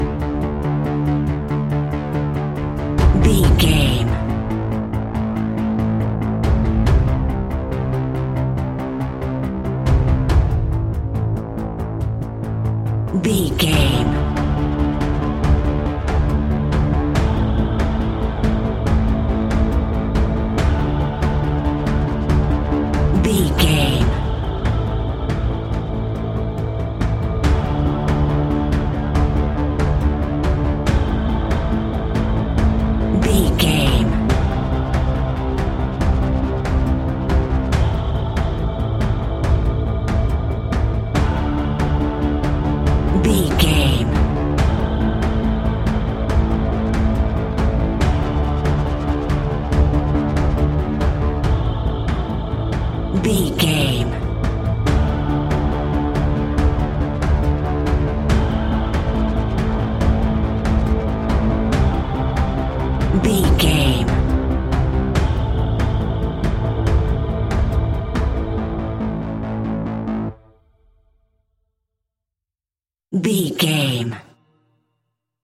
royalty free music
In-crescendo
Thriller
Aeolian/Minor
ominous
suspense
eerie
horror music
Horror Pads
horror piano
Horror Synths